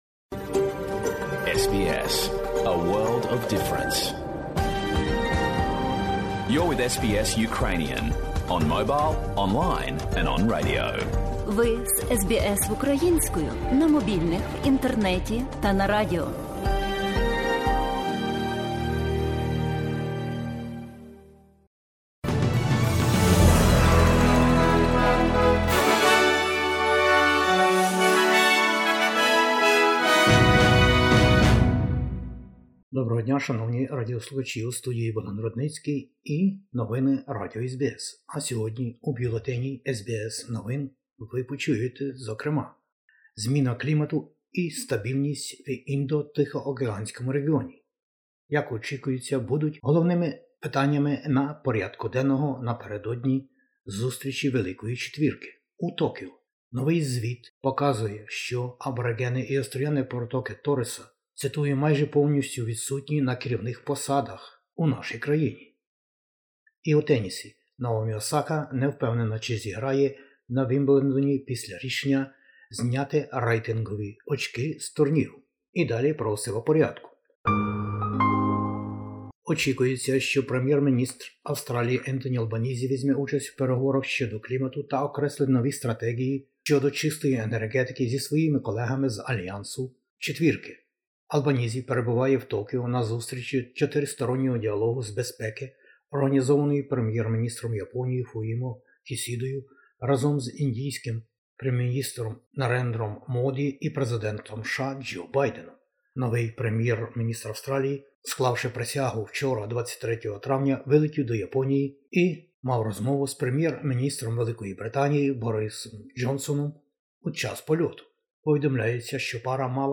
Бюлетень SBS новин українською мовою. Перші зустрічі і перша закордонна подорож нового Прем'єр-міністра Австралії.